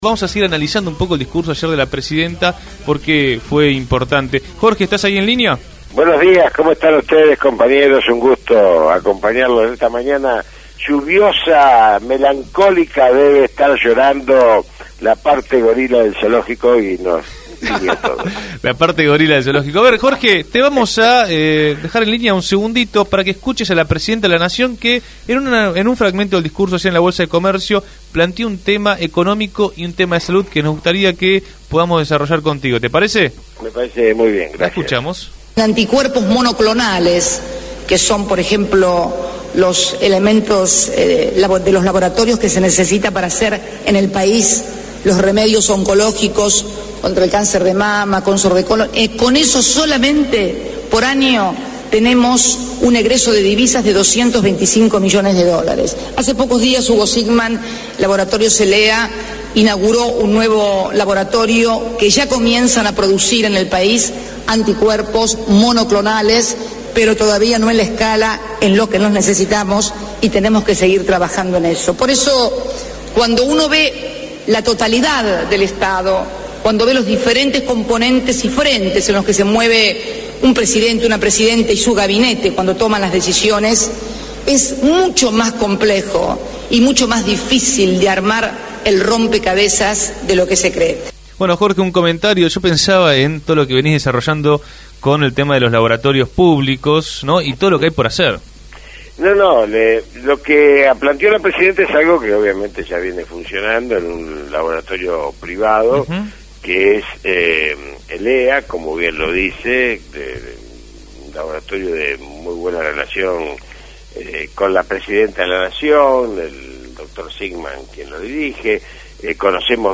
médico y militante político
En diálogo